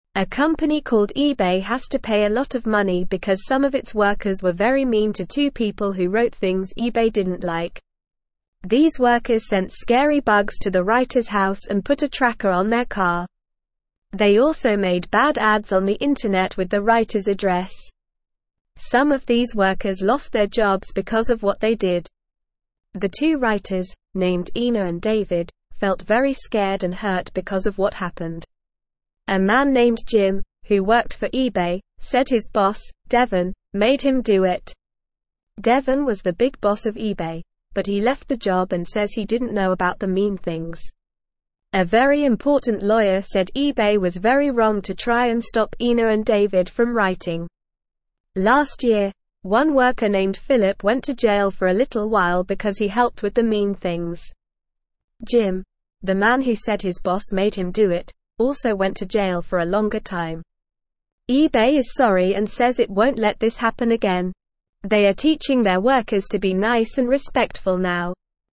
Normal
English-Newsroom-Lower-Intermediate-NORMAL-Reading-eBay-Sorry-for-Mean-Workers-Will-Pay.mp3